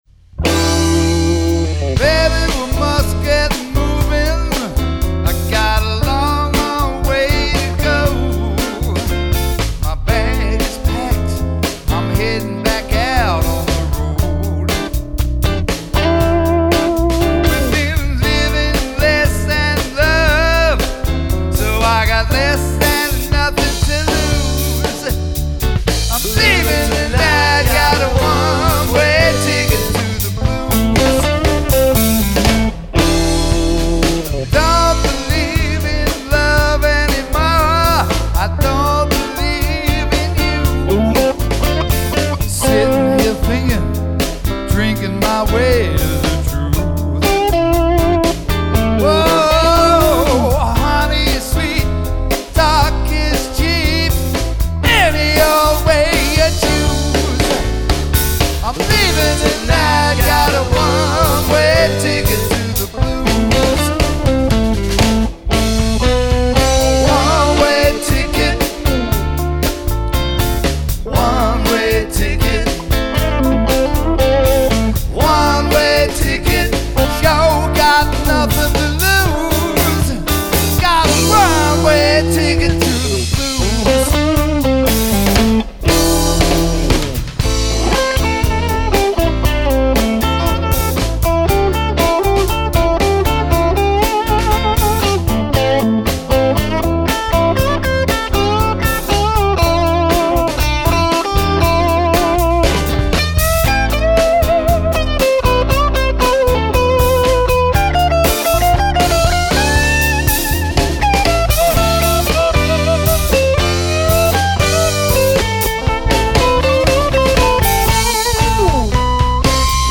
Rhythm and Blues